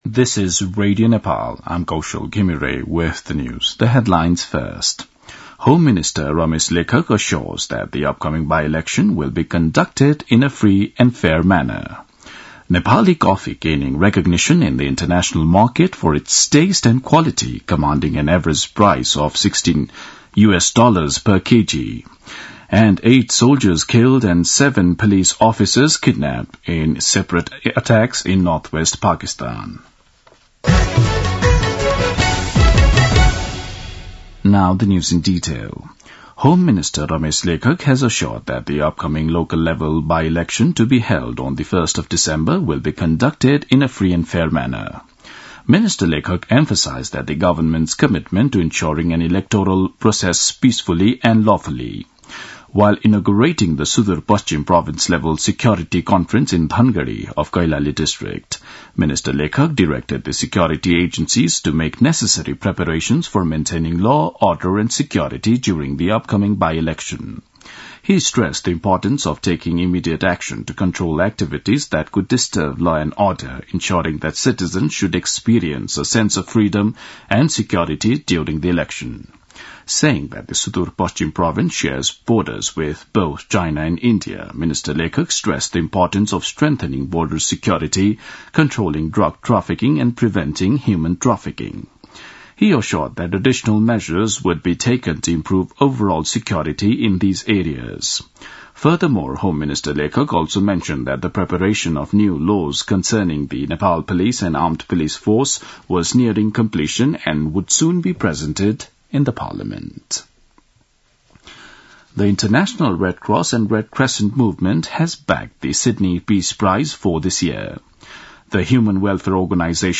An online outlet of Nepal's national radio broadcaster
दिउँसो २ बजेको अङ्ग्रेजी समाचार : ५ मंसिर , २०८१
2-pm-english-news-1-6.mp3